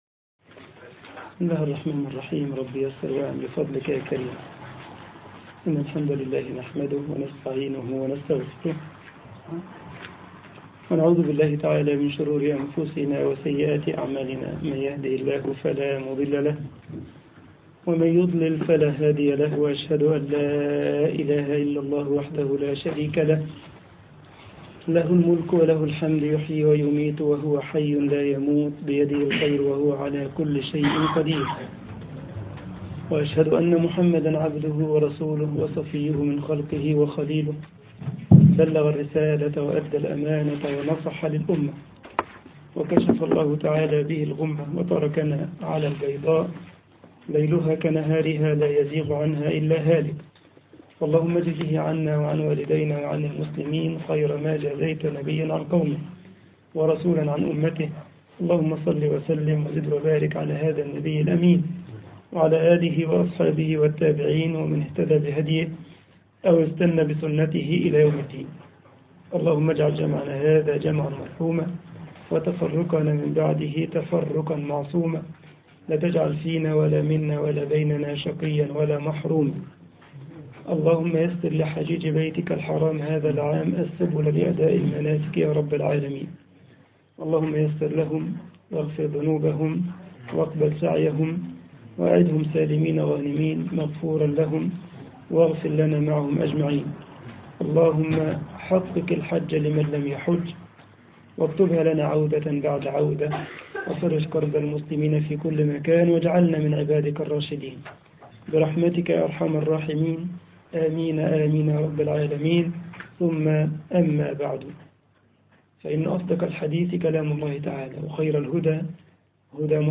مترجم للألمانية
محاضرة
مسجد مرتسش - ألمانيا